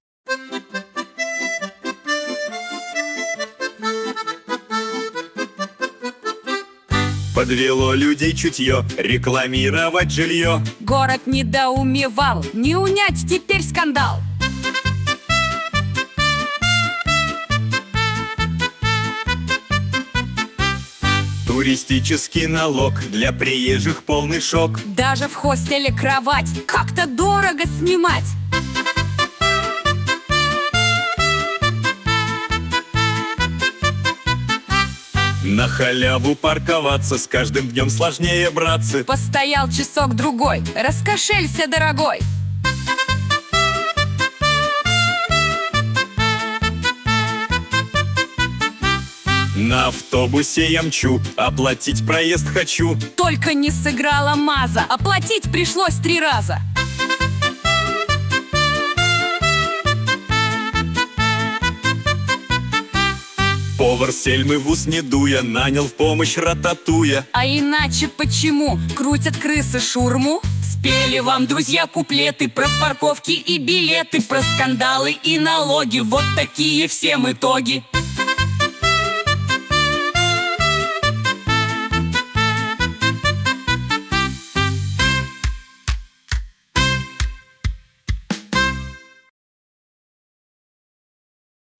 О главных и важных событиях — в виде комических куплетов